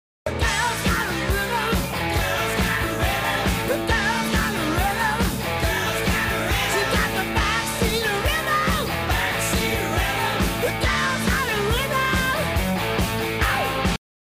so i pitched it instead